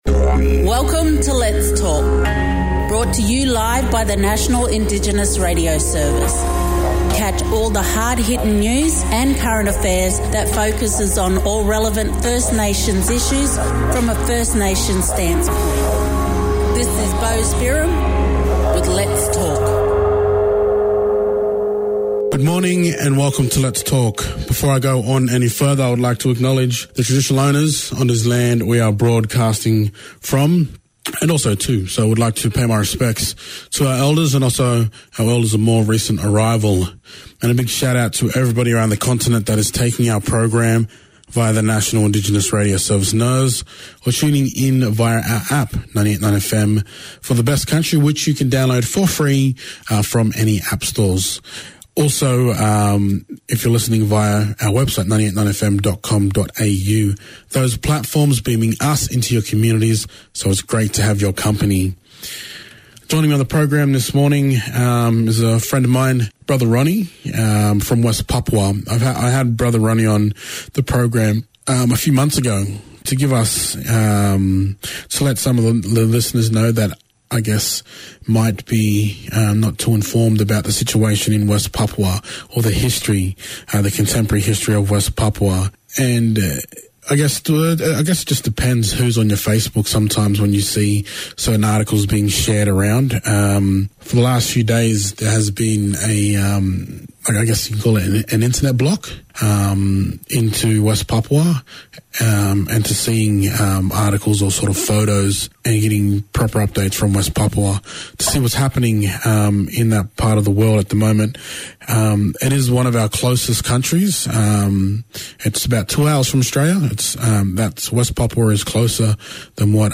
a West Papuan musician and activist